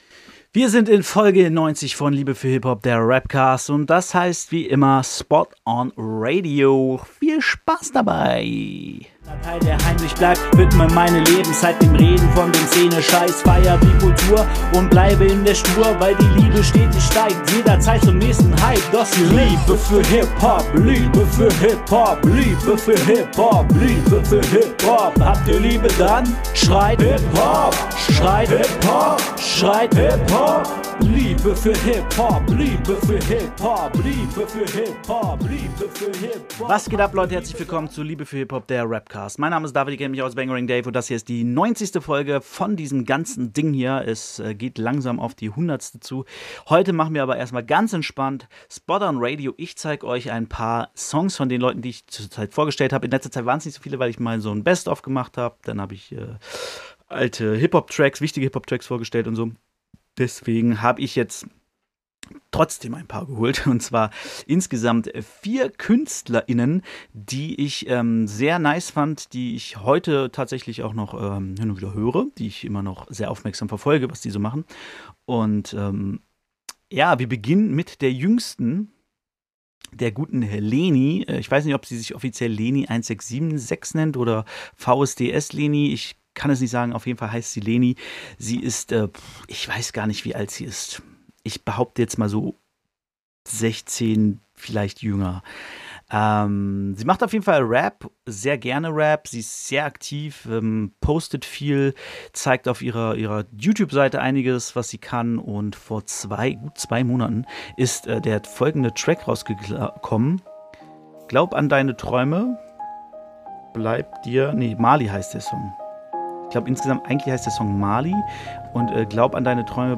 Vier Songs von 5 Künstlern, die es verdient haben, mehr Aufmerksamkeit zu bekommen.